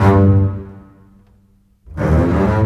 Rock-Pop 07 Bass 02.wav